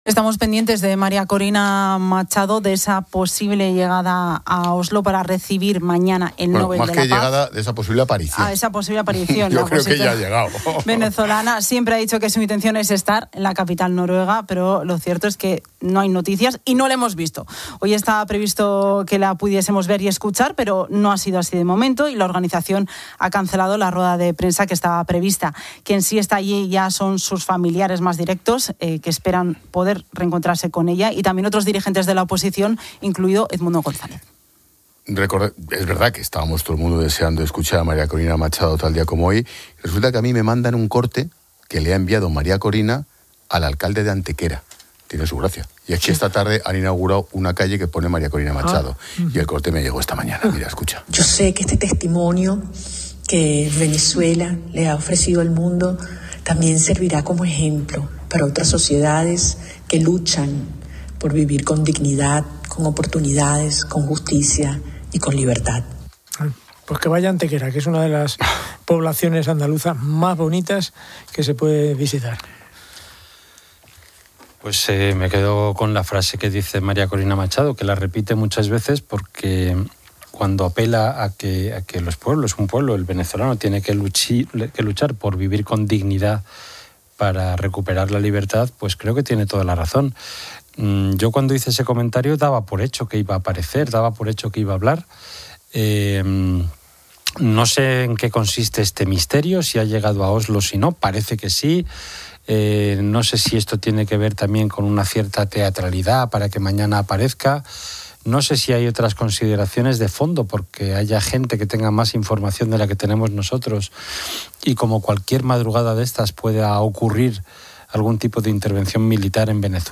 En medio de la expectación, Expósito ha compartido un audio enviado por la propia Machado al alcalde de Antequera, donde se ha inaugurado una calle en su honor: "Yo sé que este testimonio que Venezuela le ha ofrecido al mundo también servirá como ejemplo para otras sociedades que luchan por vivir con dignidad, con oportunidades, con justicia y con libertad".